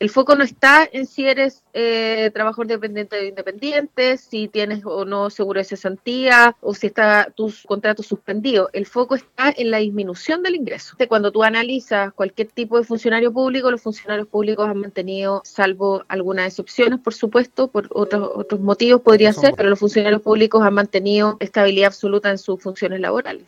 En conversación con Radio Sago, la Seremi de Desarrollo Social y Familia en la región, Soraya Said explicó los beneficios que actualmente están disponibles para la población en el contexto de la crisis sanitaria y económica que vive el país.